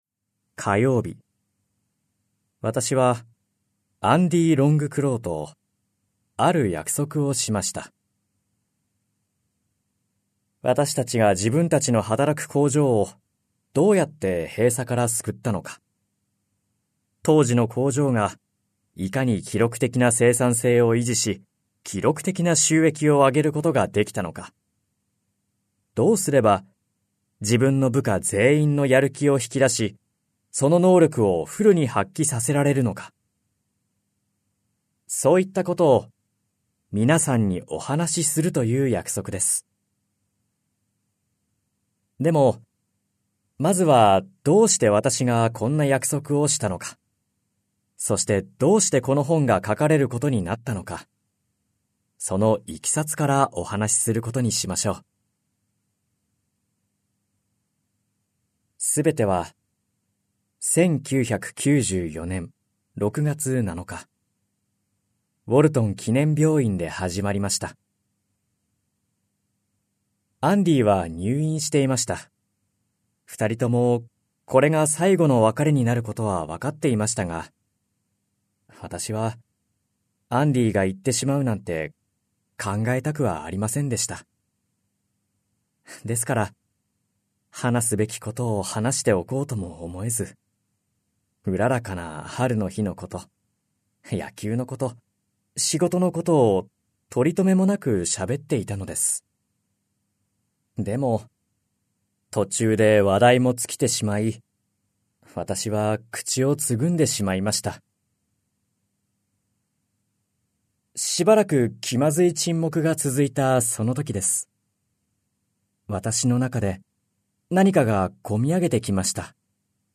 [オーディオブック] 1分間モチベーション 「仕事に行きたい！」会社にする3つのコツ